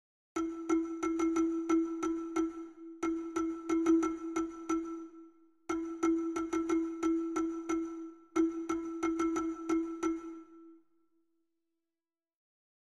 Para empezar, intenta reproducir con percusión corporal o ritmo que escoitarás no reproductor da esquerda.
Ritmo_1.mp3